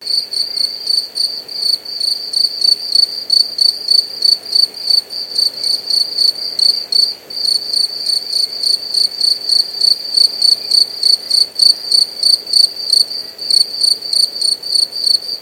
Verso di cicala di notte
Leggero rumore d'abiente con suono di cicale. Loopabile
cigada.WAV